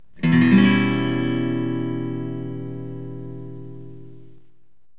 Em6.mp3